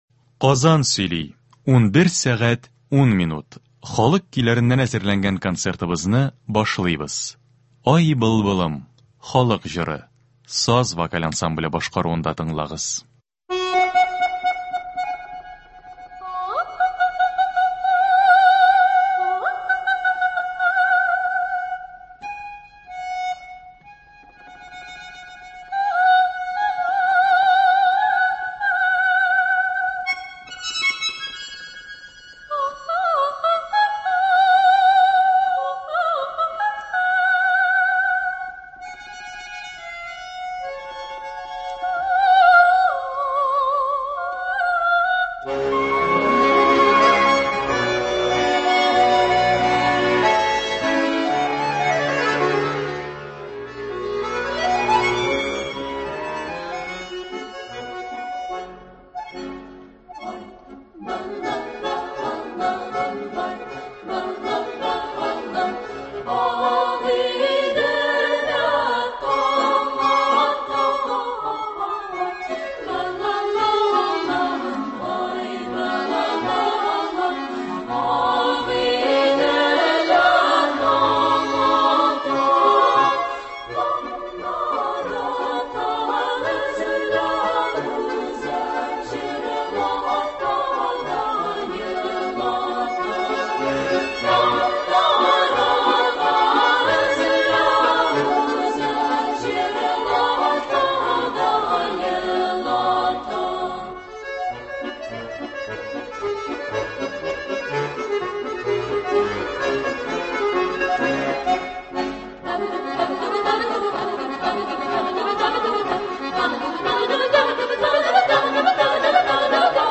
Татар халык көйләре. 22 февраль.
Бүген без сезнең игътибарга радио фондында сакланган җырлардан төзелгән концерт тыңларга тәкъдим итәбез.